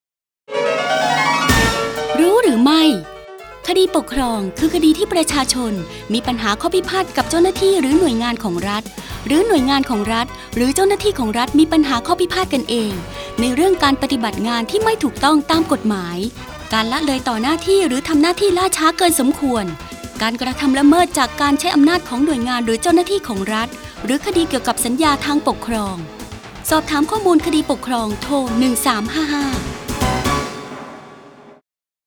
คำสำคัญ : สารคดีวิทยุ, คดีปกครอง, คดีปกครองชวนรู้